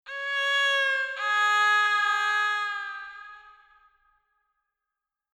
LoseSound.mp3